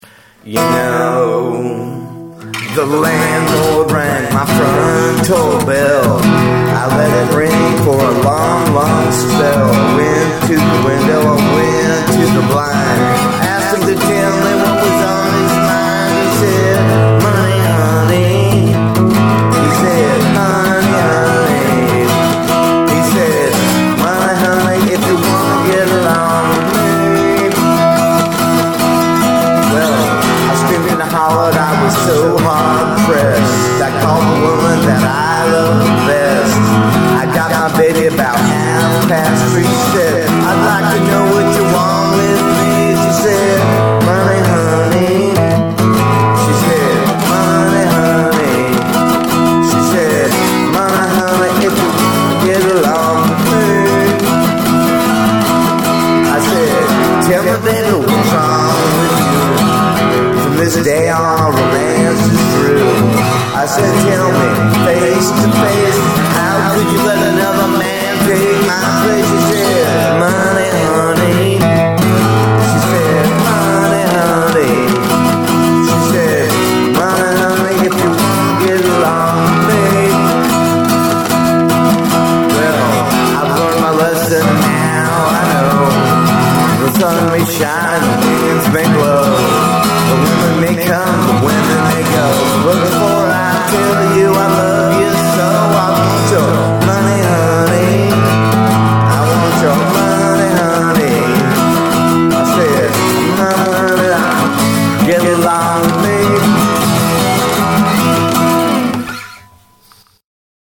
Here is another live recording.